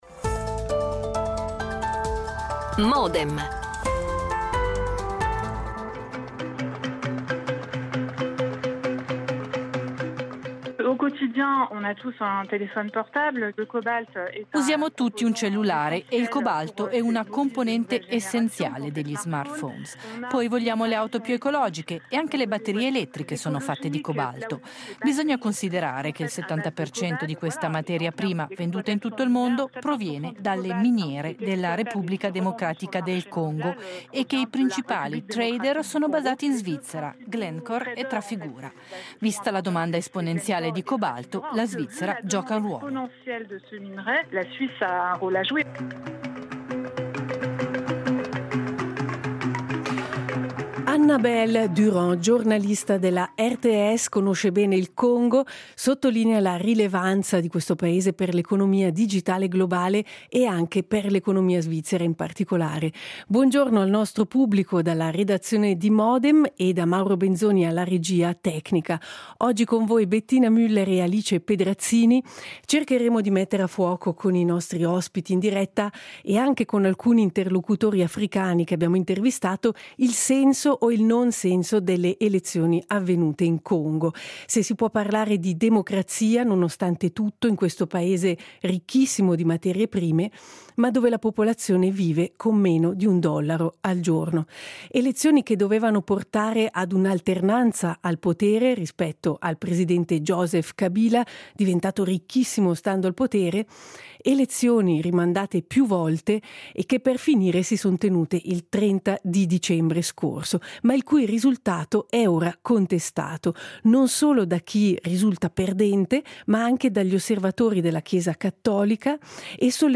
Interviste registrate a un attivista congolese che ha chiesto l’anonimato
L'attualità approfondita, in diretta, tutte le mattine, da lunedì a venerdì